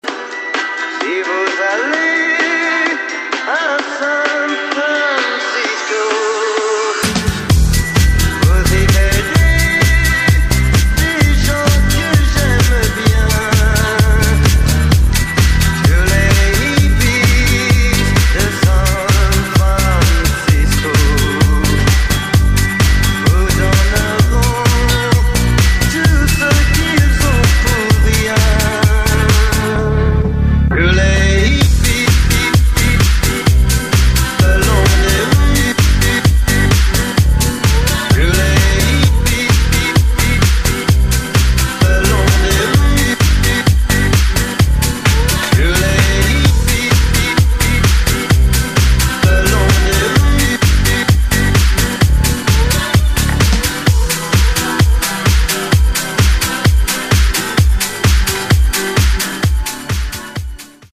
• Качество: 128, Stereo
deep house
dance
играющий мелодичный электро-хаус.